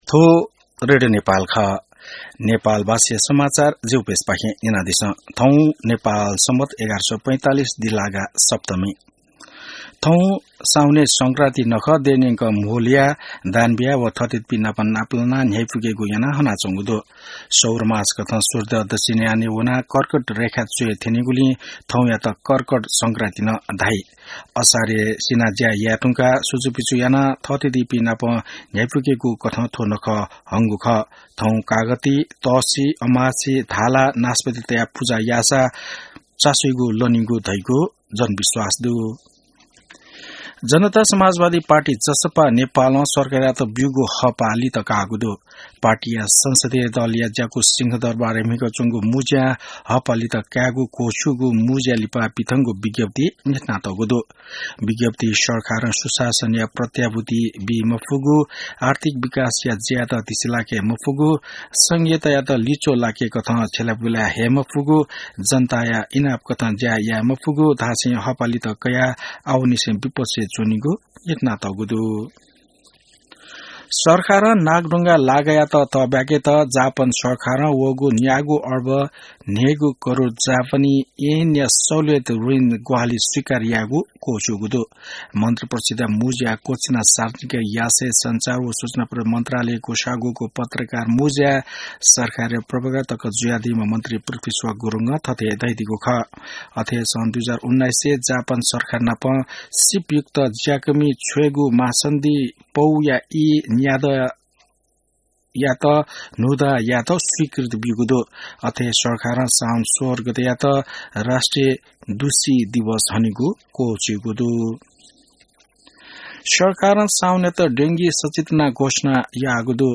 नेपाल भाषामा समाचार : १ साउन , २०८२